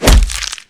kick1.wav